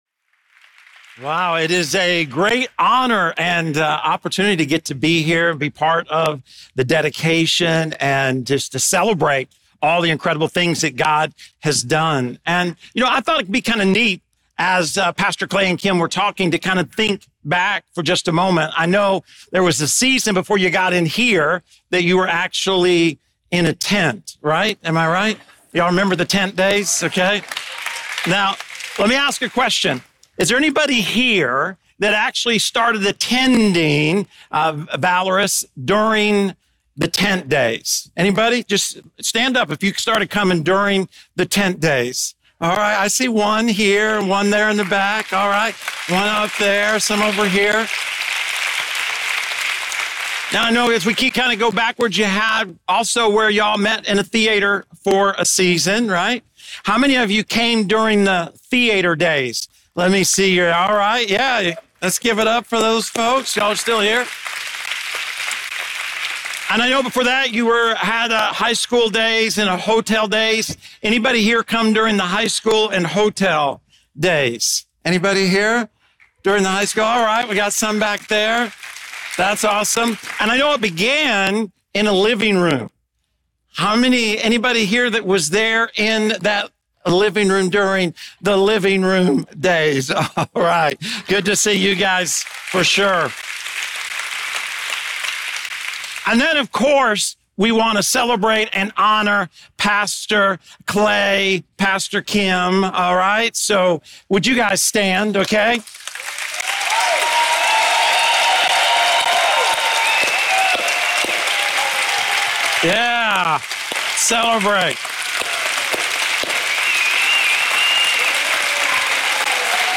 Dedication Service | Valorous Church | Valorous Church